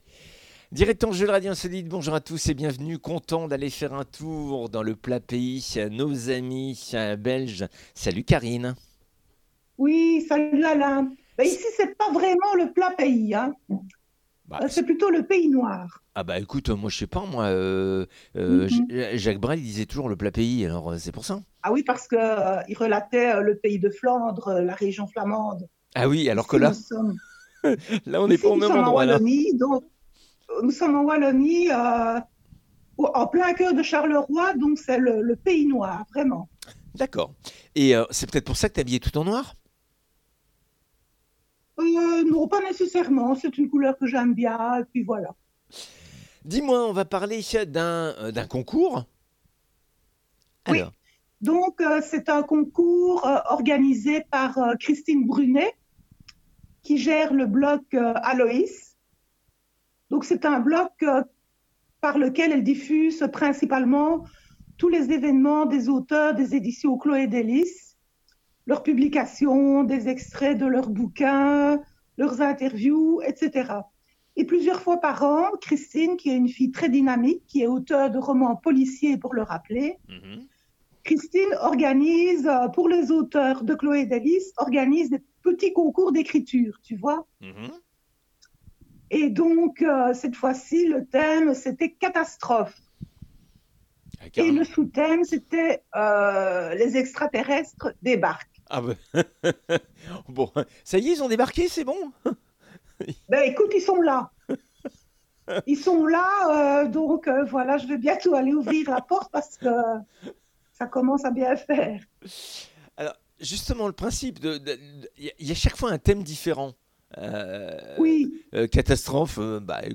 lecture des 6 textes du concours "Catastrophes naturelles " des Editions Aloys